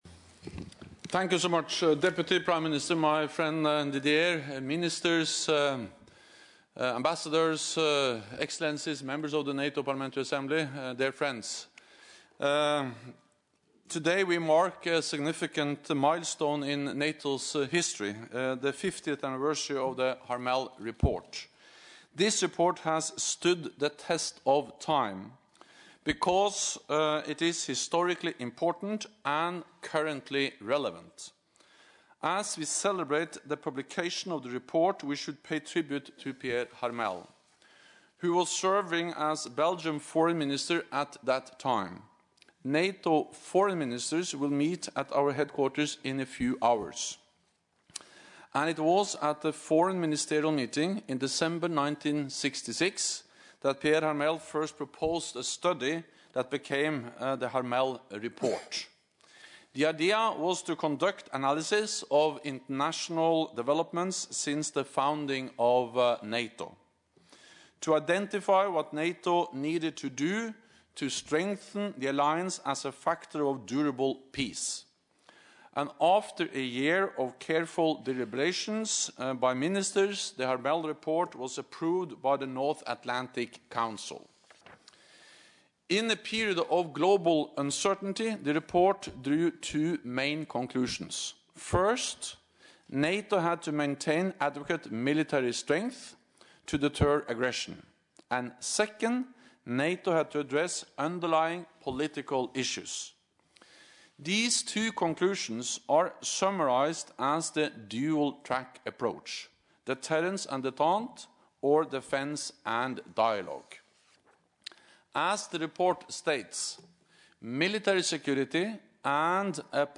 The NATO Secretary General, Mr. Jens Stoltenberg, delivered a keynote speech today at an event sponsored by Belgian Ministry of Foreign Affairs to commemorate the 50th anniversary of the “Harmel Report.”